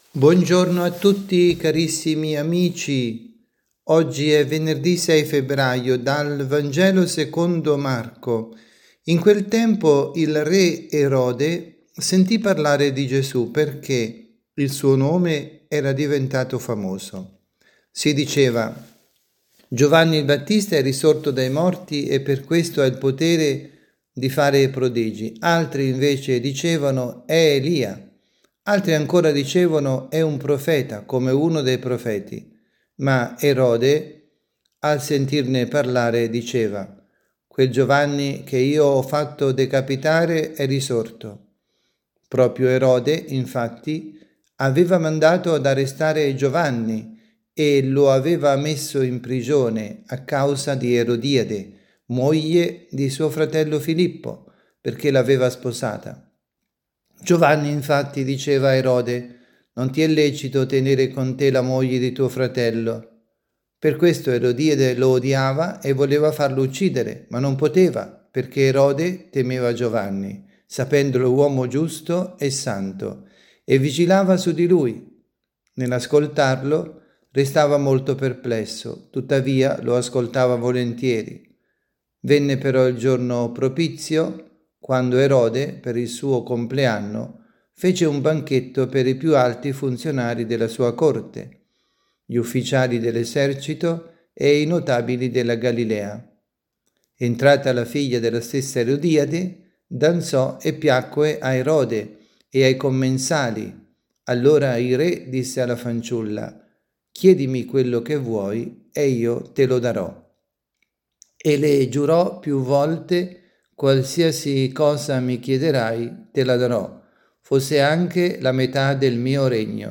avvisi, Catechesi, Omelie, Ordinario